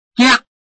拼音查詢：【饒平腔】giag ~請點選不同聲調拼音聽聽看!(例字漢字部分屬參考性質)